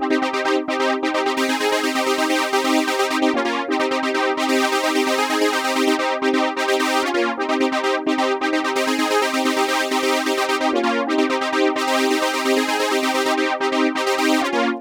FILTER OBIE 2.wav